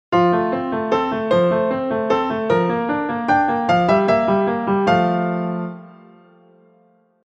Consider how ordinary the phrase would sound if the second measure just stayed on an F major chord: